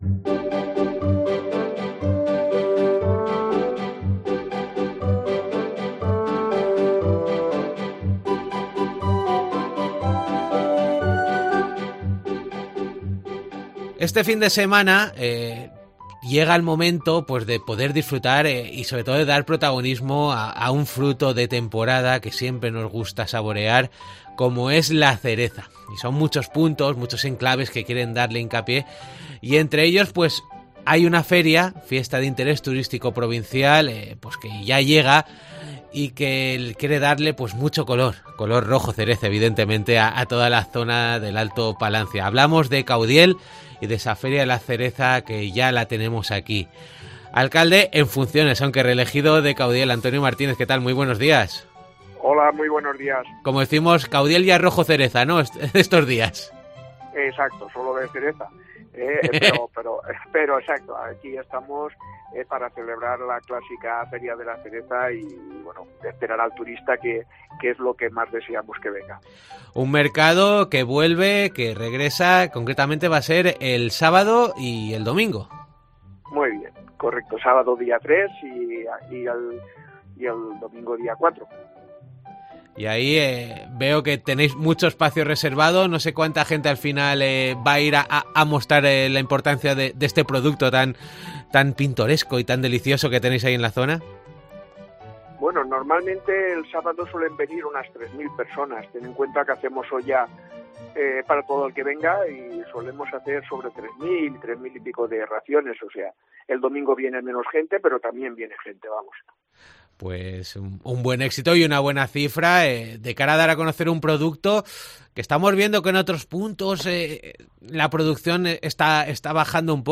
desde la Feria de la Cereza de Caudiel y la actualidad del sector.